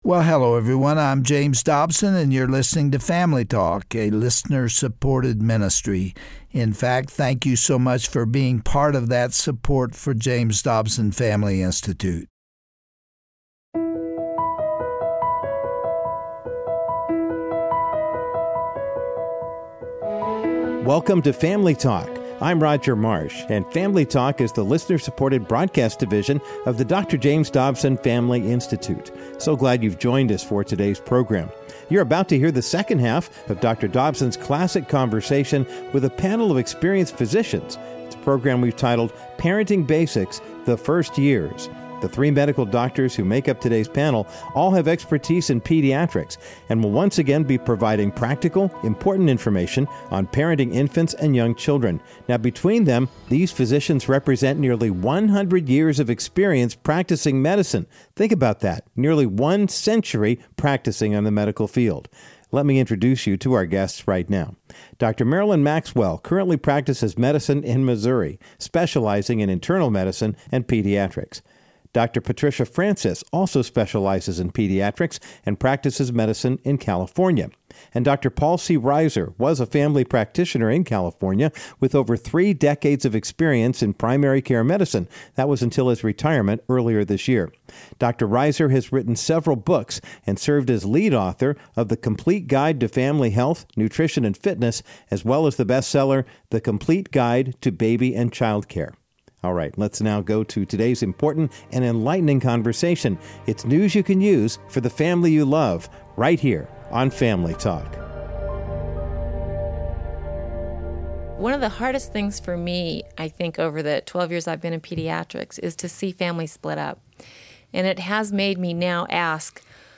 On today’s edition of Family talk, hear another day of practical wisdom for those expecting their first baby, or for the parents of a newborn. Dr. James Dobson continues his discussion with a panel of pediatric doctors.